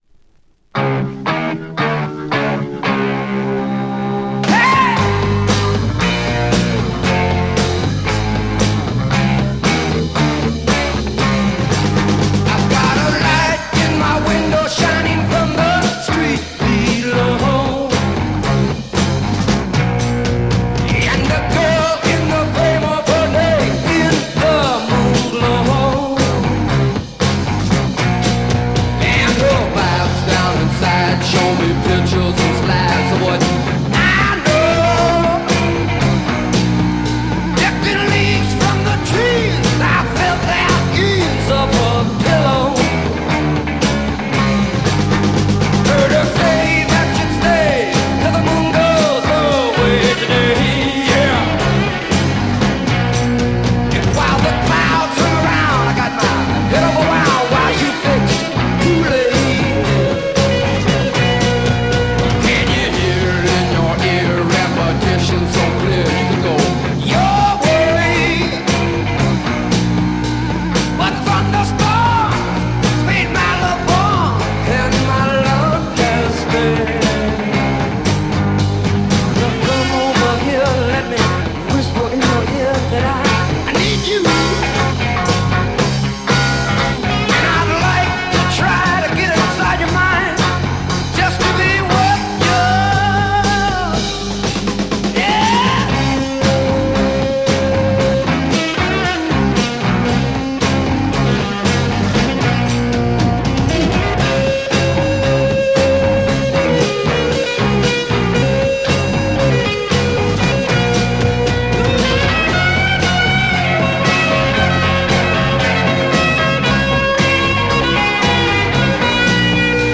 Killer Hard rock smasher
Great smashing hard rock single, rare French sleeve press!